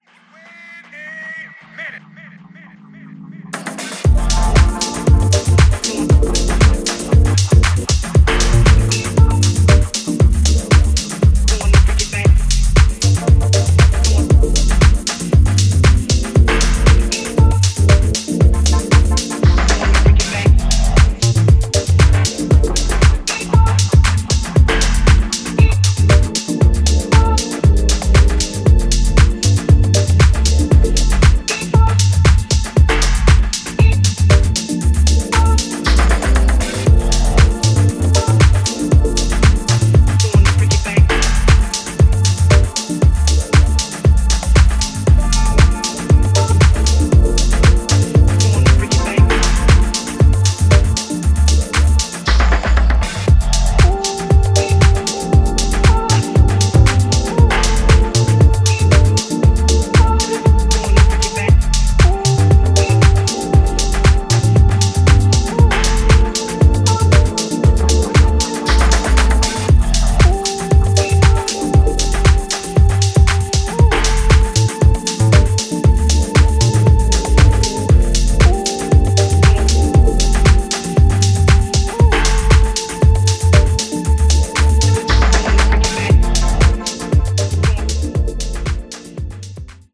ジャンル(スタイル) HOUSE / DEEP HOUSE